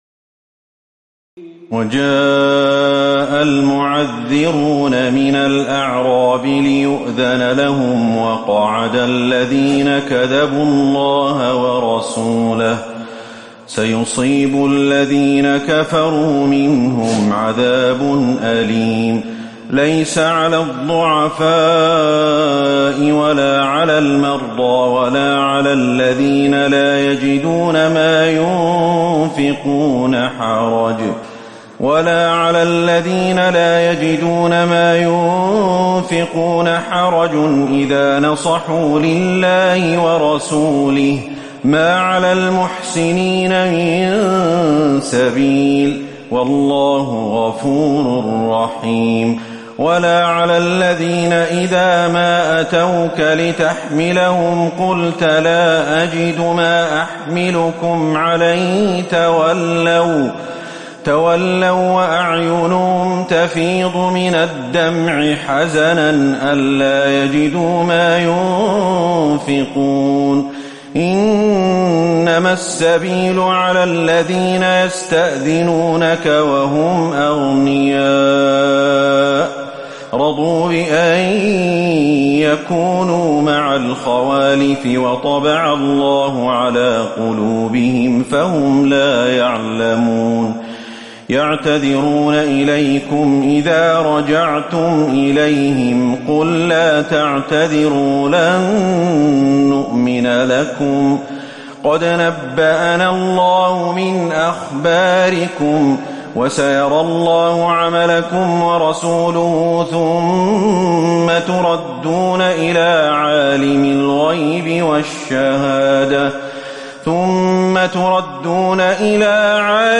تراويح الليلة الحادية عشر رمضان 1438هـ من سورتي التوبة (90-129) و يونس (1-36) Taraweeh 11 st night Ramadan 1438H from Surah At-Tawba and Yunus > تراويح الحرم النبوي عام 1438 🕌 > التراويح - تلاوات الحرمين